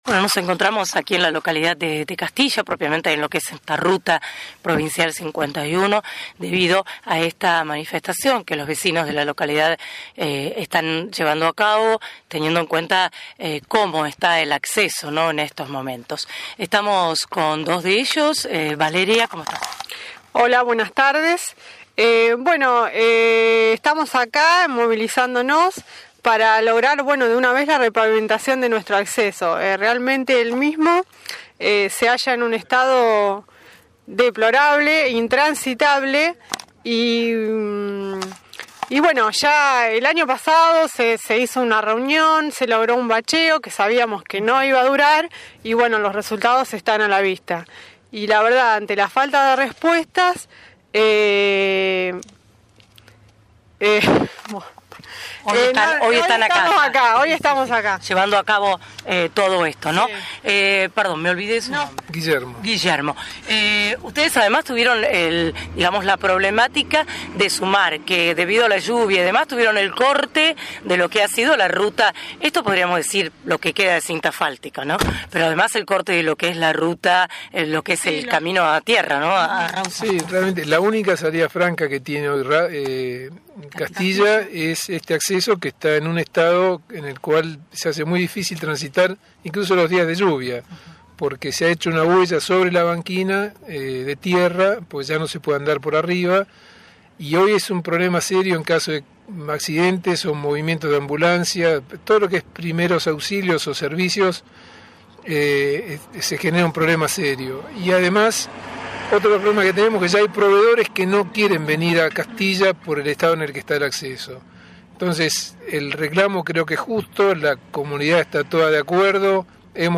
Vecinos de Castilla en dialogo con la prensa.
El resto de la nota se completa con los audios de vecinos de Castilla y O´Higgins, presentes en la concentración.